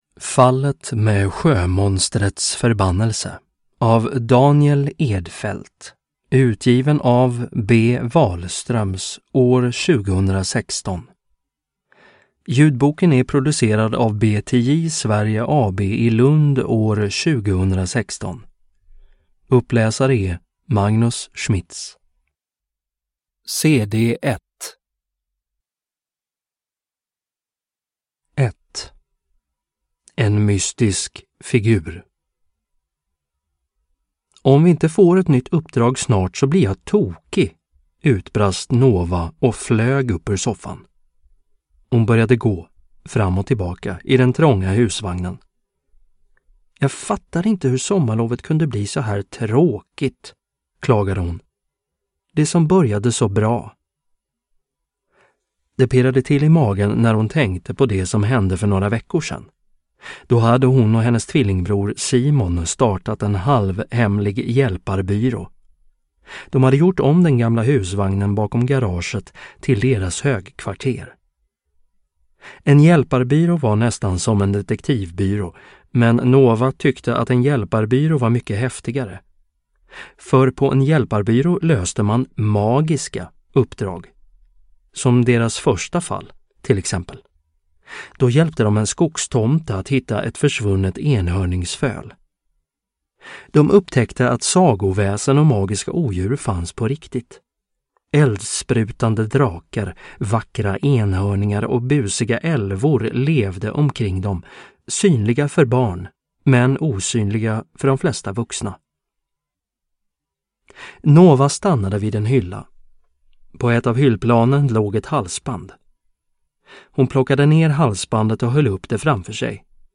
Hjälparbyrån. Fallet med sjömonstrets förbannelse – Ljudbok – Laddas ner